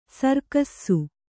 ♪ sarkassu